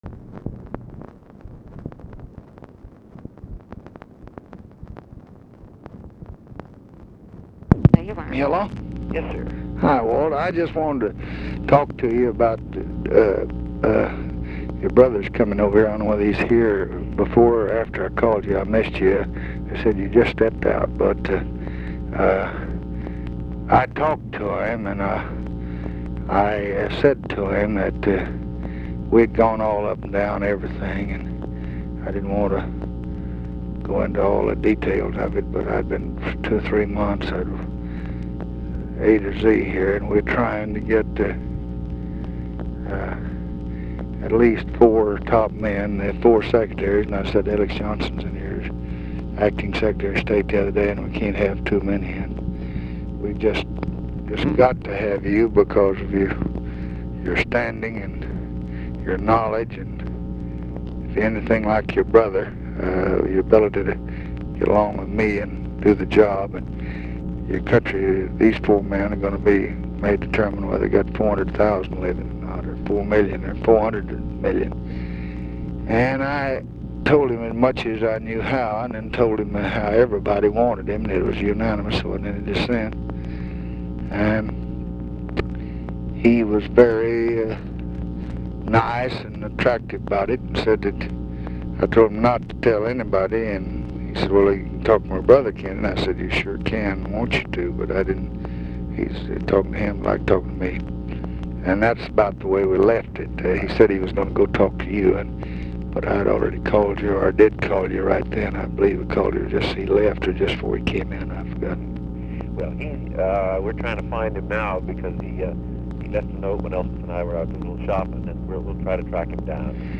Conversation with WALT ROSTOW, September 17, 1966
Secret White House Tapes